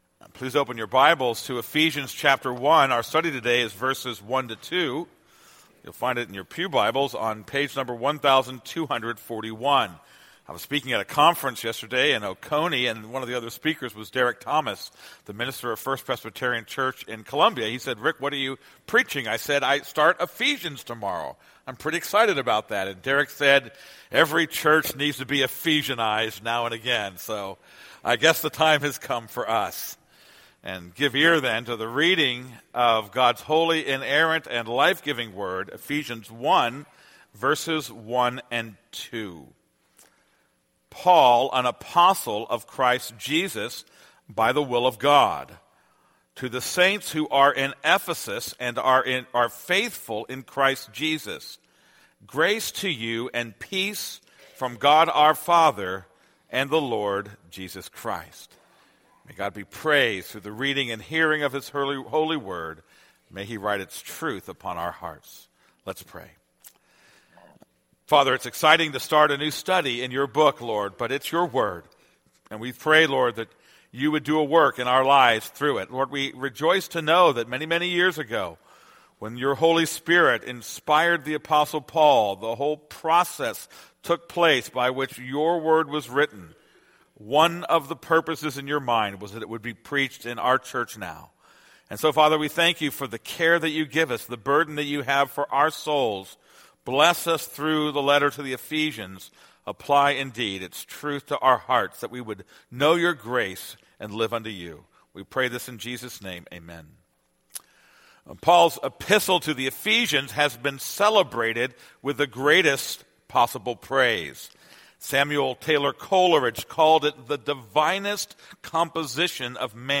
This is a sermon on Ephesians 1:1-2.